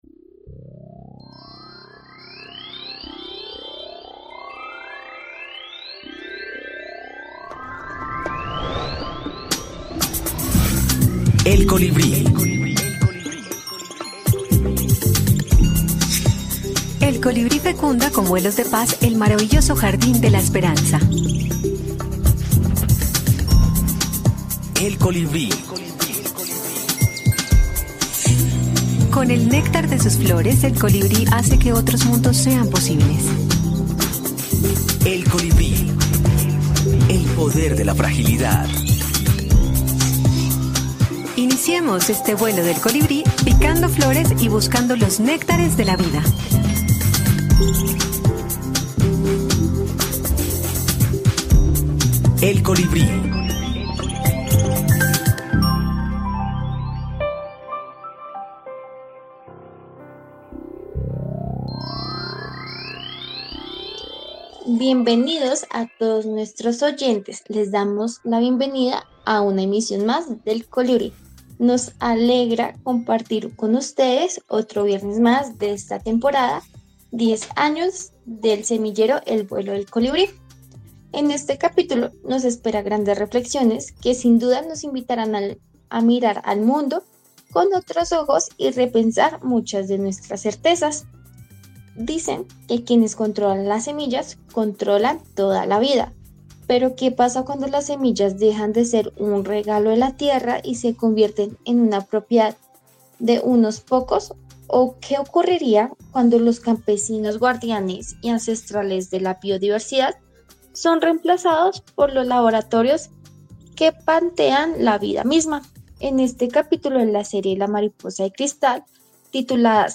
Que la buena música no falte en Bogotanísimos.